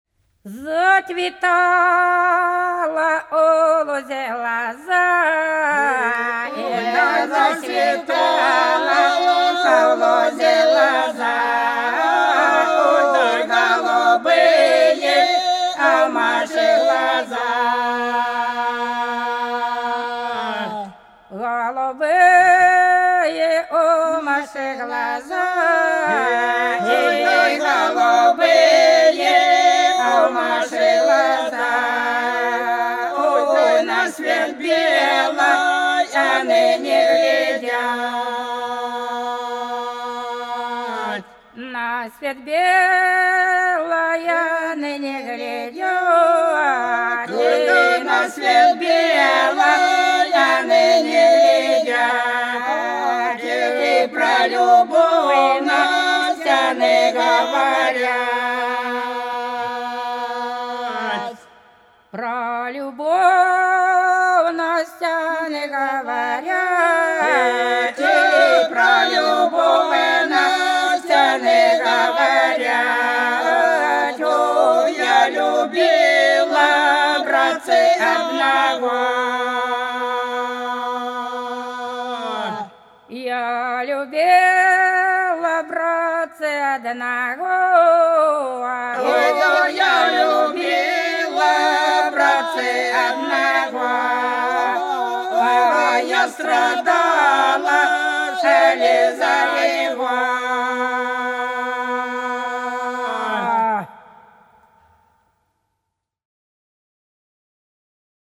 По-над садом, садом дорожка лежала Зацветала у лозе лоза - протяжная (с.Плёхово, Курской области)
02_Зацветала_у_лозе_лоза_(протяжная).mp3